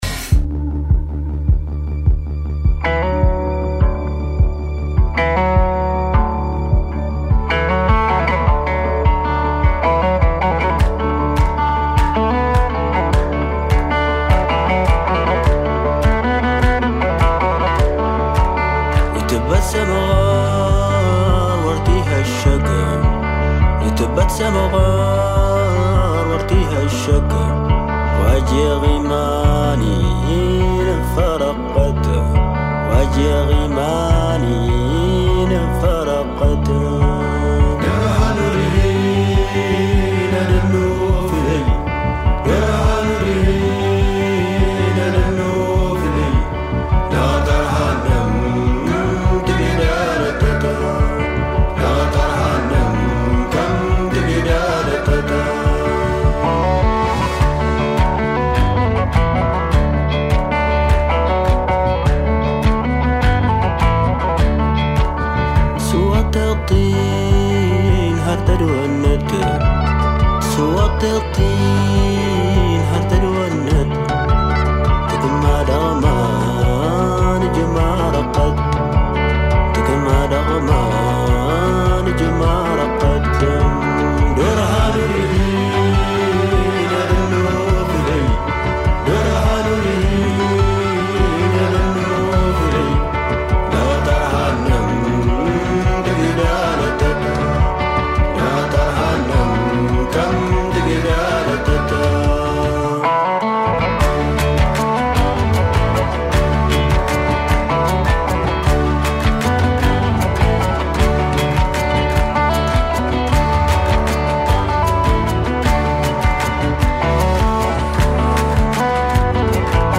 Blues d'Afrique Una puntata dedicata al deserto e al blues che arriva dalle sue forme più tradizionali, quelle dei musicisti di una parte dell'Africa che, negli anni, hanno definito un modello da cui si attinge tra psichedelia, tradizione e ispirazioni da cui attingono a piene mani molti musicisti Occidentali.